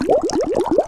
Sfx Boat Through Water Sound Effect
sfx-boat-through-water-5.mp3